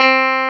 CLAV2HRDC4.wav